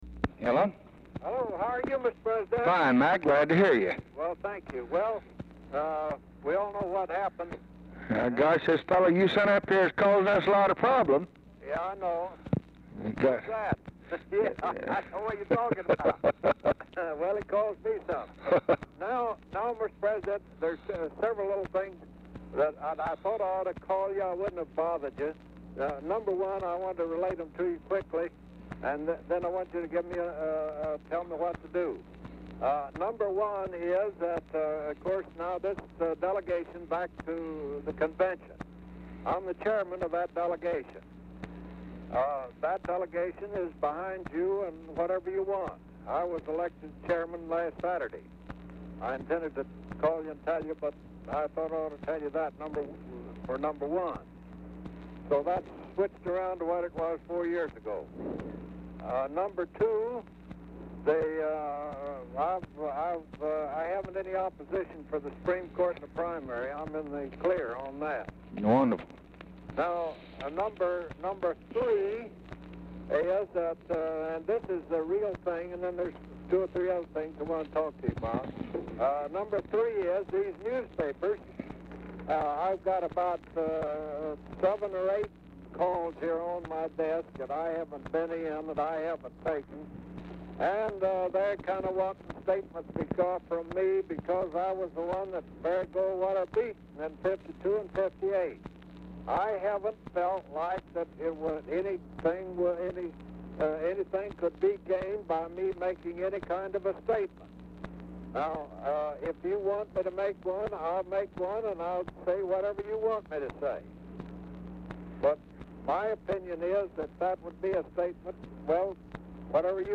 Telephone conversation # 4255, sound recording, LBJ and ERNEST MCFARLAND, 7/16/1964, 8:20PM | Discover LBJ
Format Dictation belt
Location Of Speaker 1 Oval Office or unknown location